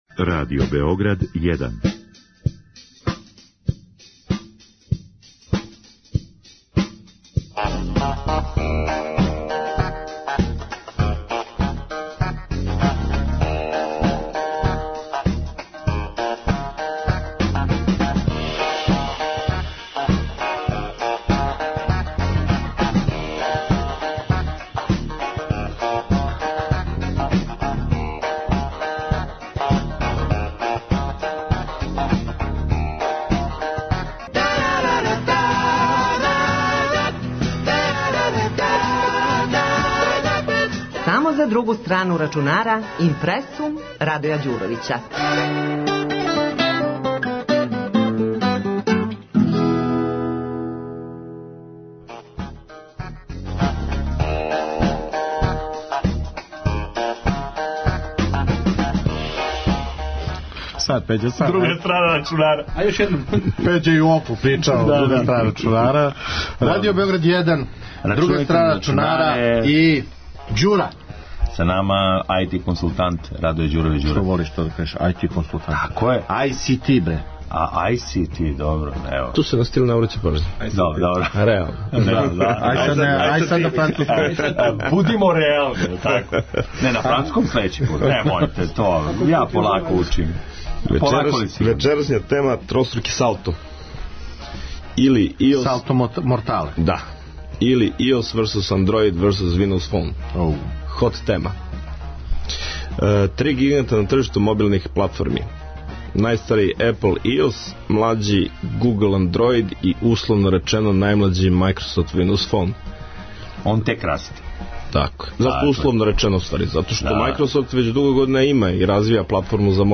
Audio zapis iz emisije „Druga strana računara“ emitovane 5.4.2014.godine na talasima Radio Beograd 1 – IMPRE§UM – možete preslušati ovde: